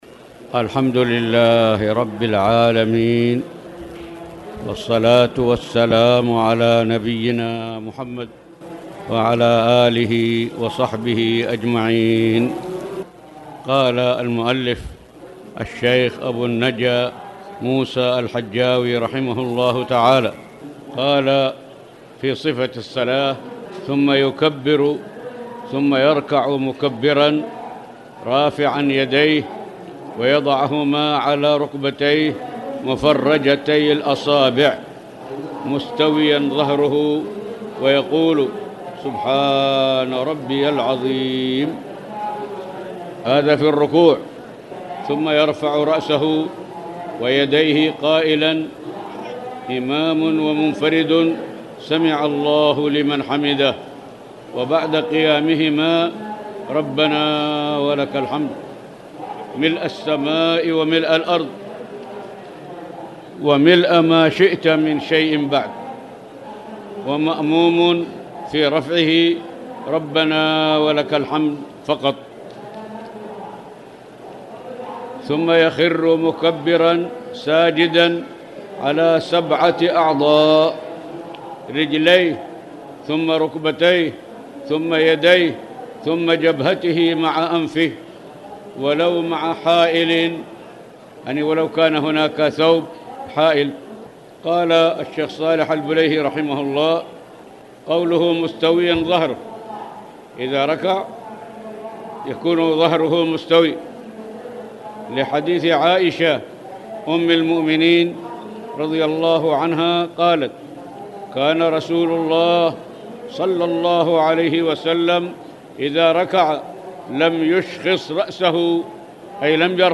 تاريخ النشر ١٥ جمادى الآخرة ١٤٣٨ هـ المكان: المسجد الحرام الشيخ